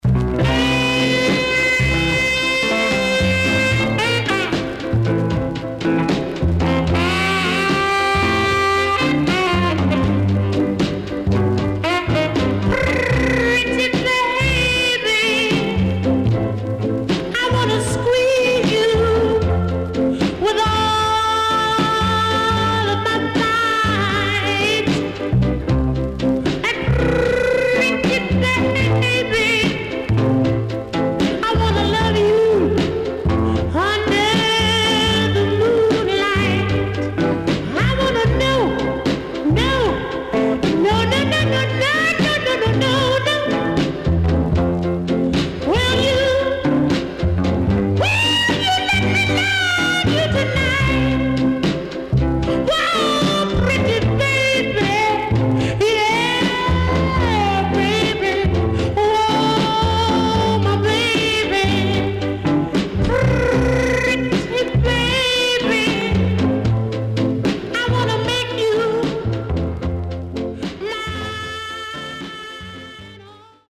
HOME > SOUL / OTHERS
SIDE A:所々チリノイズがあり、少しプチノイズ入ります。